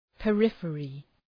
Προφορά
{pə’rıfərı}